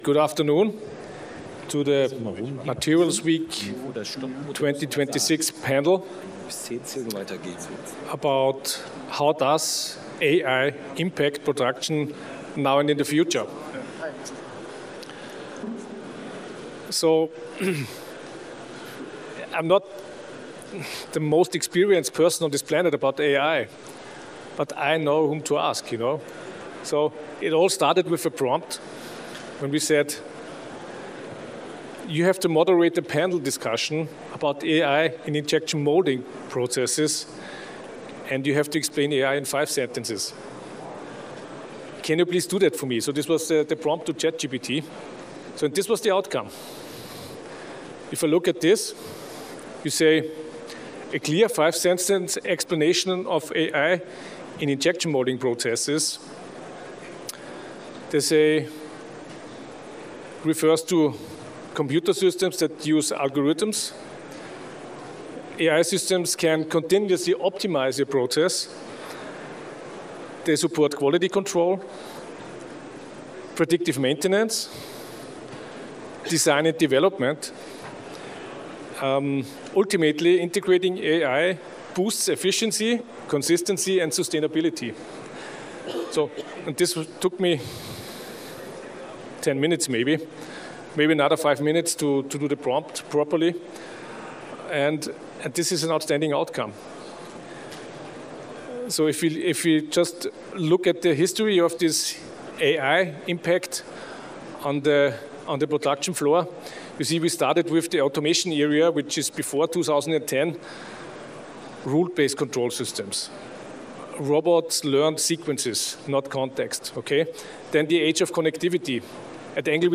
Panel Discussion Archives - Materials Week Europe